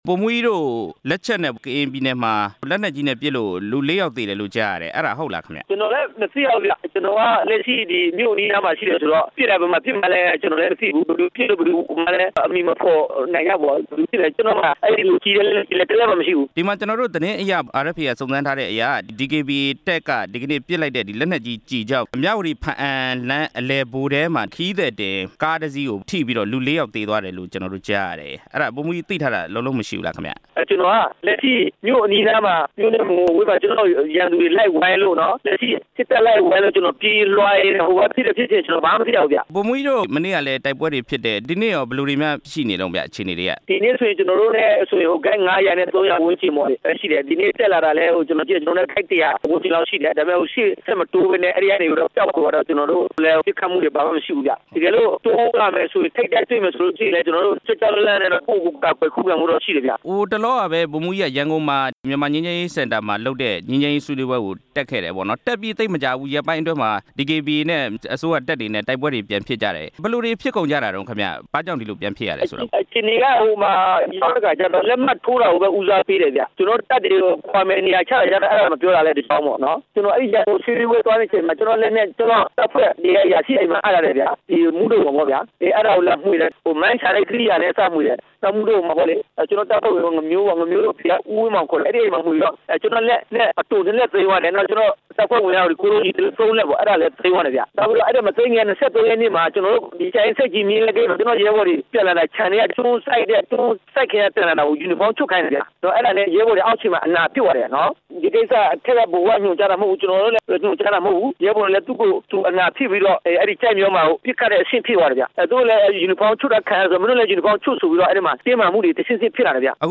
ကရင်ပြည်နယ် စစ်ရေးတင်းမာမှု နောက်ဆုံးအခြေအနေ မေးမြန်းချက်